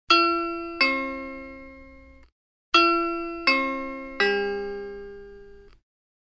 Honeywell D845 – Boston / elektronisk dørklokke (kablet)
• Signal med tre ulike toner
• Justerbart volum opptil 75 dB (a)